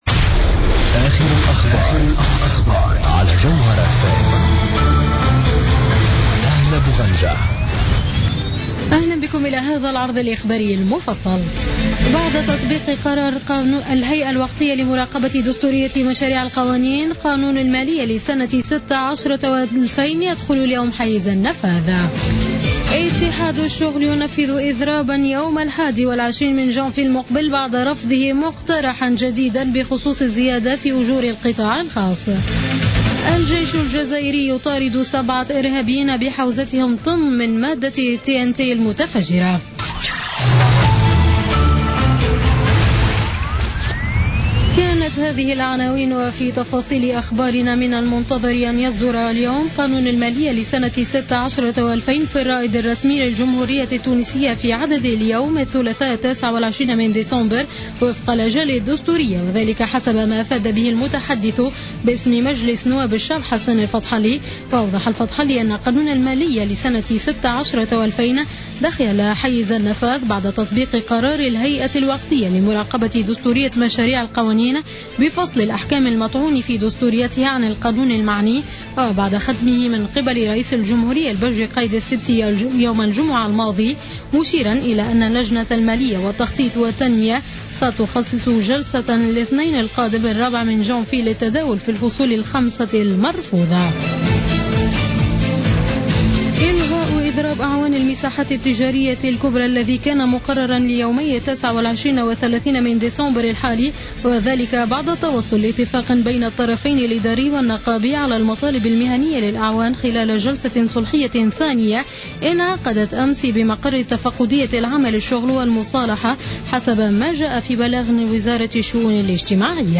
نشرة أخبار منتصف الليل ليوم الثلاثاء 29 ديسمبر 2015